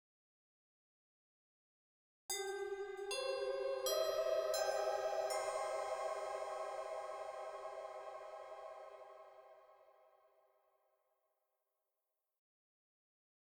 Жуткий фрагмент из фильма ужасов